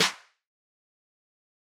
XO The Host Snare 2.wav